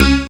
37ie01syn-c#.wav